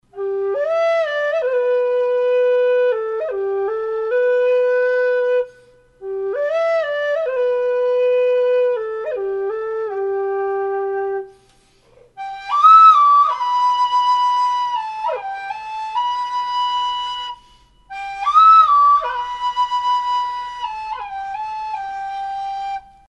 Альт G (пластик)
Альт G (пластик) Тональность: G
Неприхотливый пластиковый альт. Обладает отличным балансом и хорошей отзывчивость.